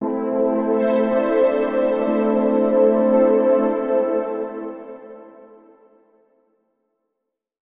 SYNTHPAD002_PROGR_125_A_SC3.wav
1 channel